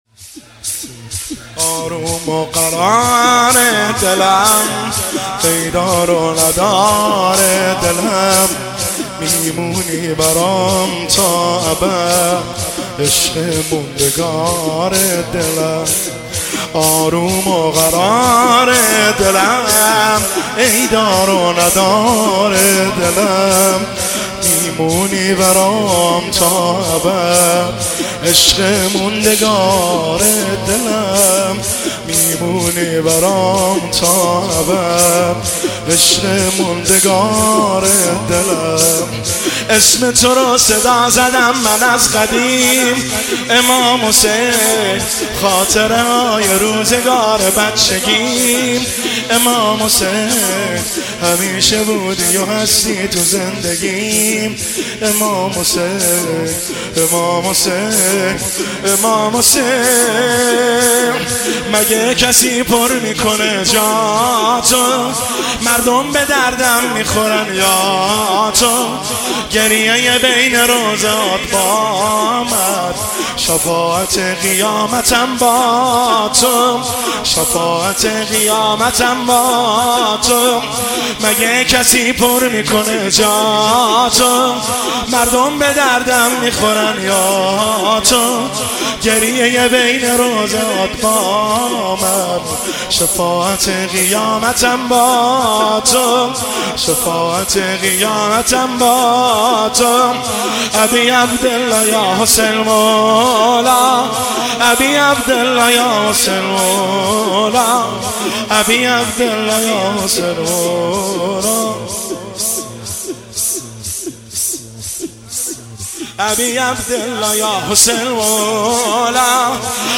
شور – شب اول محرم الحرام 1404
هیئت خادم الرضا قم